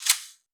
TC2 Perc6.wav